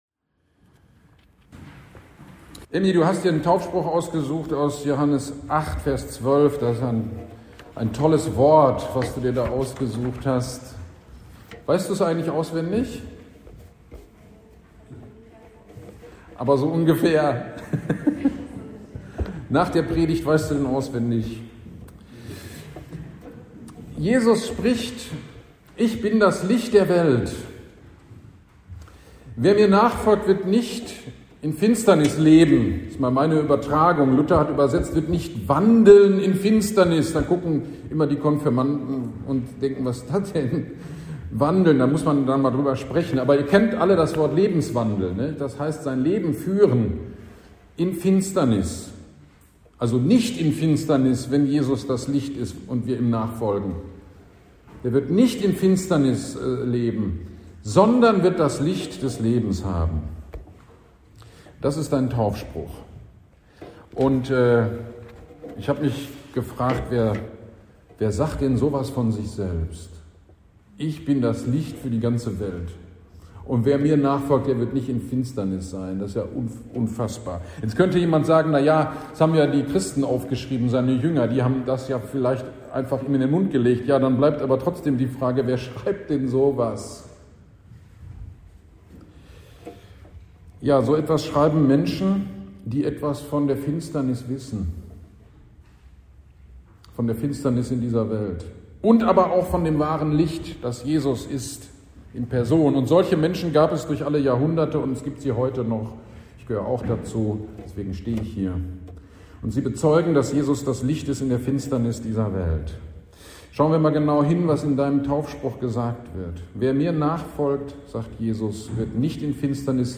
Tauf-GD am 16.07.23 Predigt zu Johannes 8,12
Taufpredigt-zu-Johannes-812.mp3